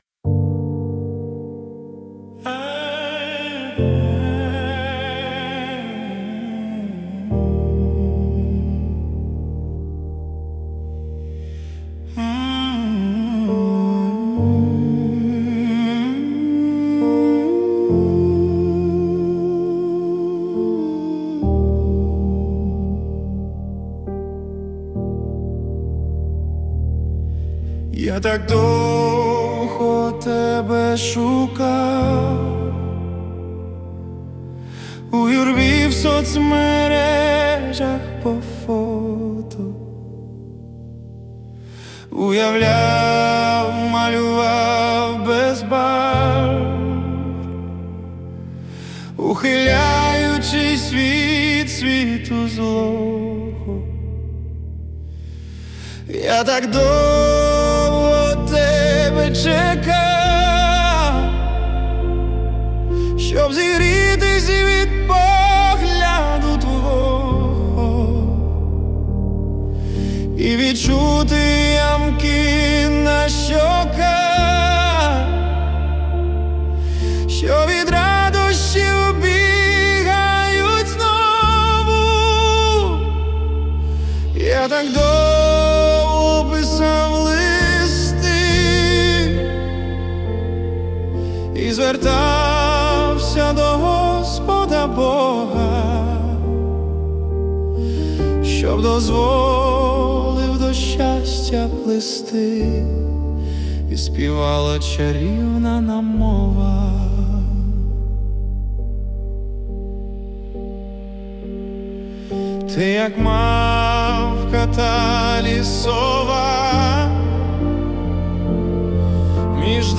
Музика і вокал ШІ - SUNO AI v4.5+